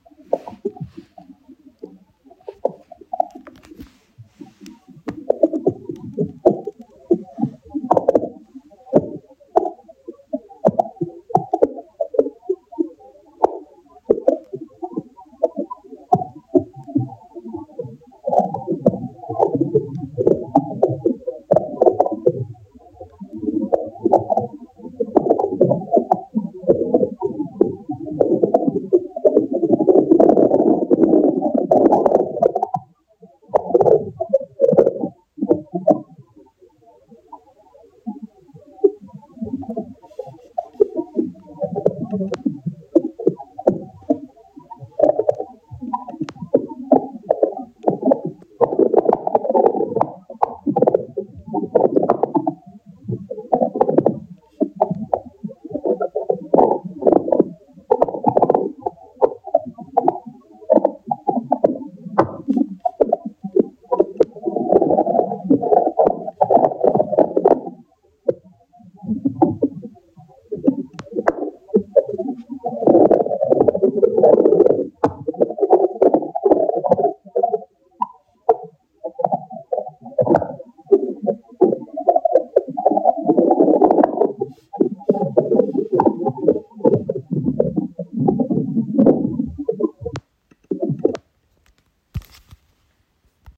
Сложнее и неоднороднее акустический профиль оказался у почв, которые были более здоровыми, то есть содержали большее количество разных живых организмов.
Исследователи сравнили звуки здоровой почвы с подземной вечеринкой или «концертом в стиле рейв с хлопками мыльных пузырей и щелчками».
Они оказались похожи на «подземный рейв» / © Jake M. Robinson et al., Journal of Applied Ecology
Healthy-soil-sounds.mp3